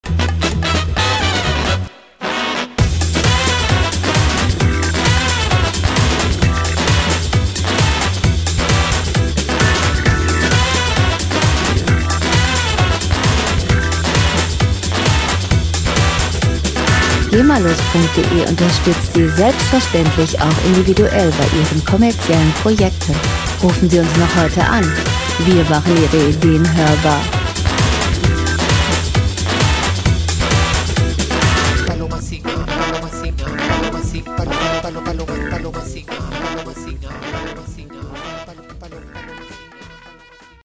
Hintergrundmusik - Quiz und Shows
Musikstil: Latin Jazz
Tempo: 132 bpm
Tonart: E-Moll
Charakter: aufgedreht, wild
Instrumentierung: Blechbläser, Hammond, Schlagzeug